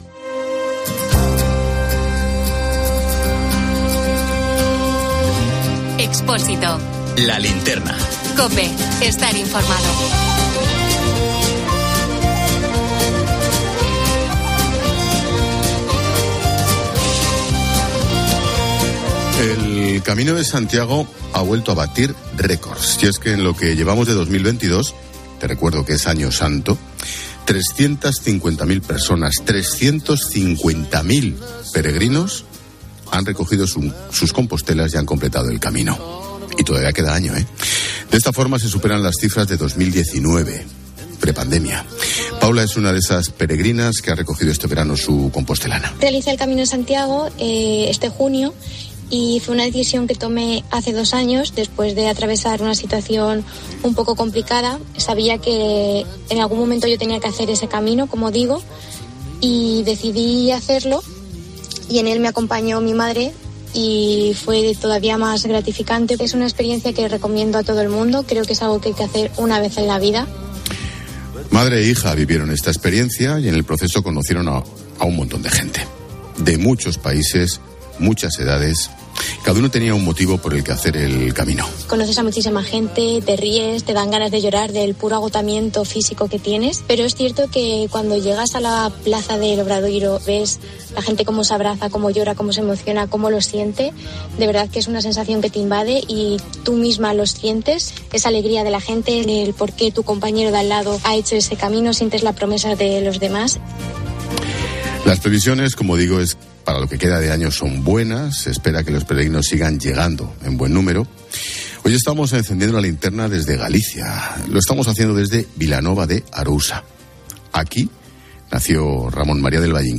Gonzalo Durán fue el primer entrevistado por Ángel Expósito en el programa especial de La Linterna de COPE desde la salida de la ruta Mar de Santiago
Entrevista de Ángel Expósito al alcalde de Vilanova de Arousa, Gonzalo Durán, en La Linterna de COPE
La formación en hostelería que se ofrece en la localidad, una anécdota que Expósito recordó del expresidente del Gobierno Mariano Rajoy o las fiestas del municipio de la comarca de O Salnés han formado parte de la conversación con el regidor popular en un programa especial emitido en directo desde el Auditorio Valle-Inclán.